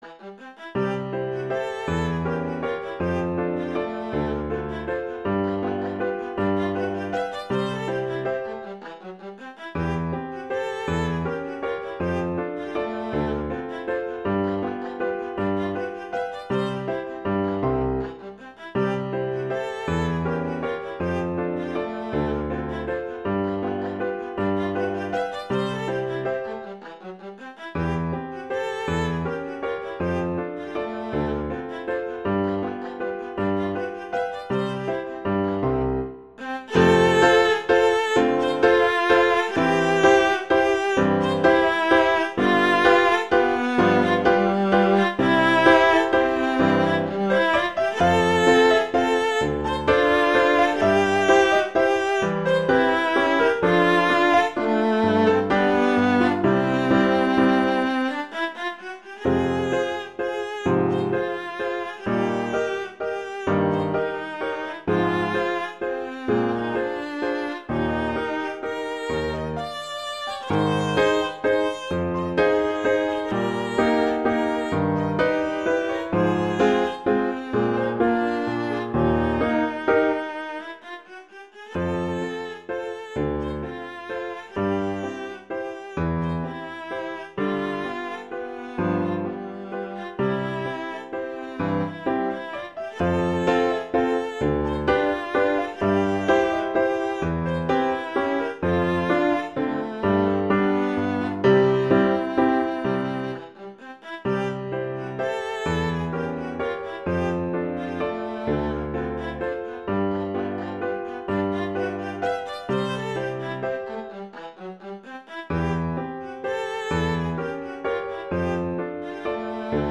classical
C major
♩=160 BPM